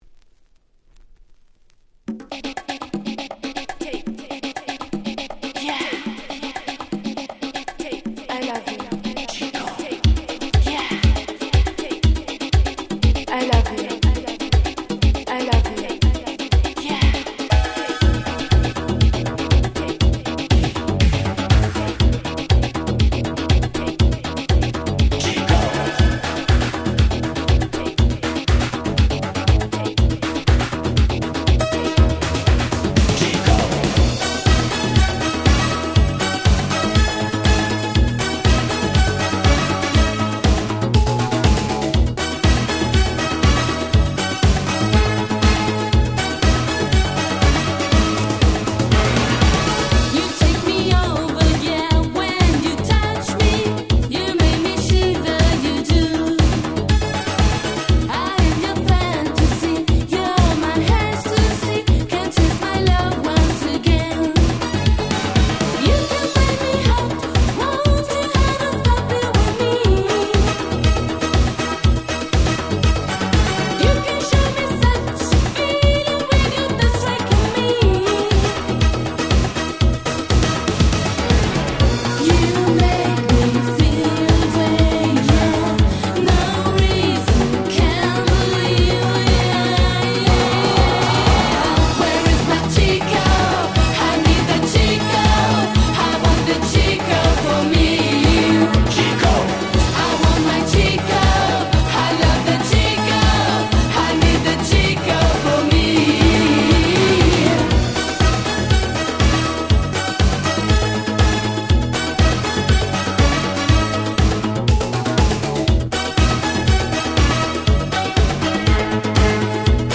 88' Smash Hit Italo Disco !!